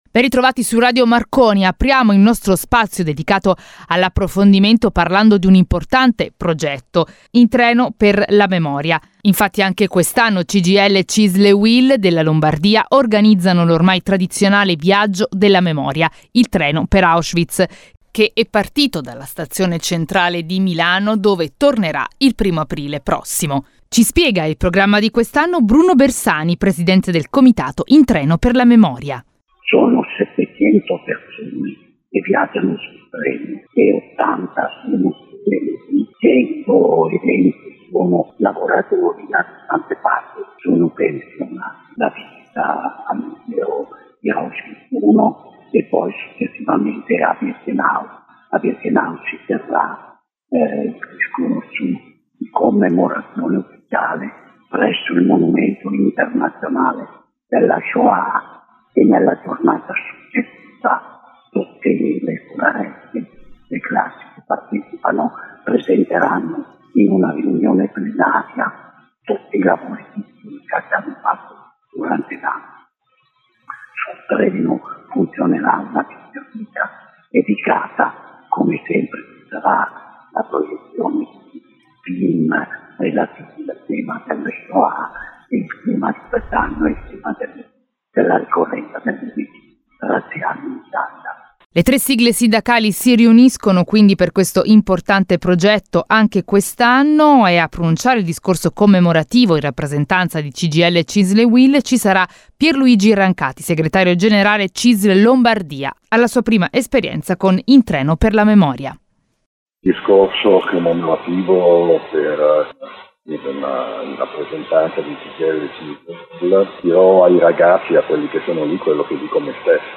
Questa settimana intervista